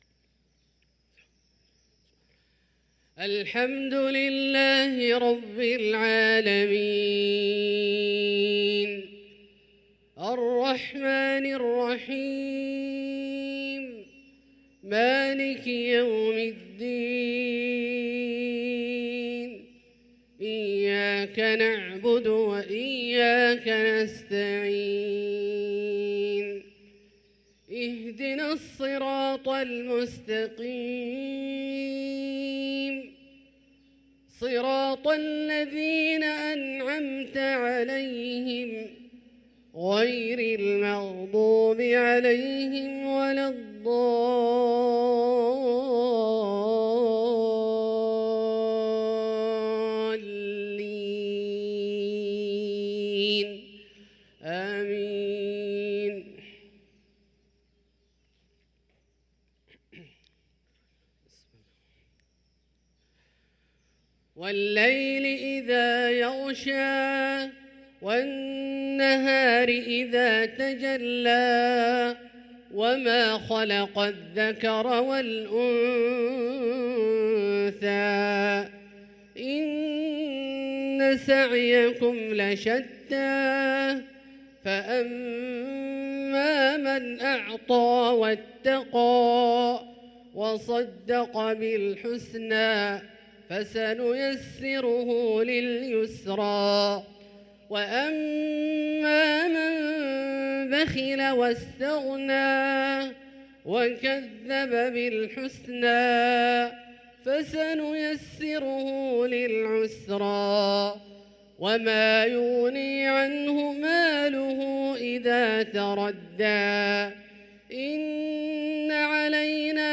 صلاة المغرب للقارئ عبدالله الجهني 25 جمادي الأول 1445 هـ